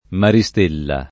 [ mari S t % lla ]